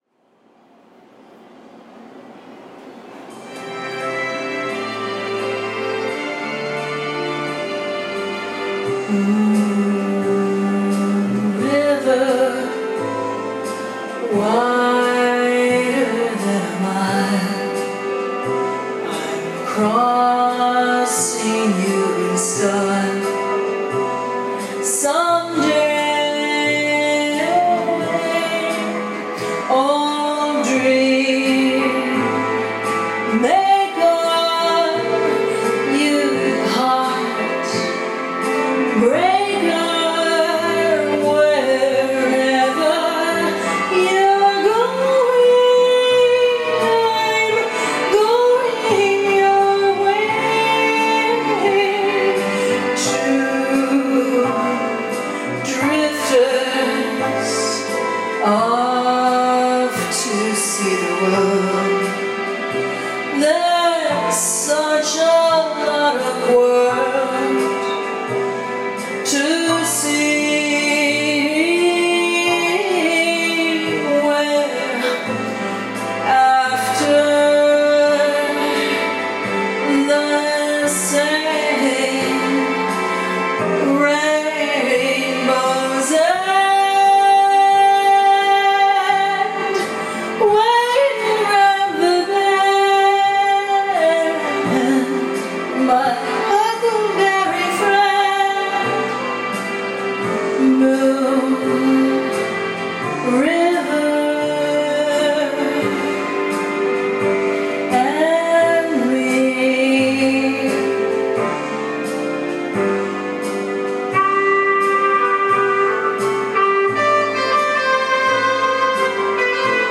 I, uh, went to the karaoke box by myself today.